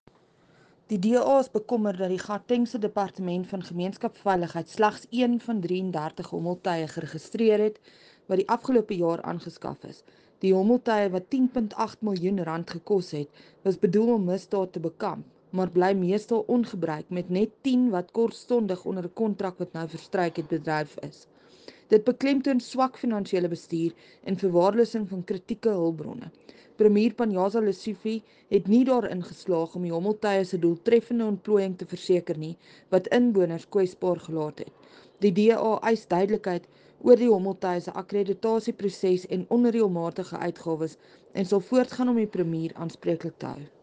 Afrikaans soundbites attached by Crezane Bosch MPL.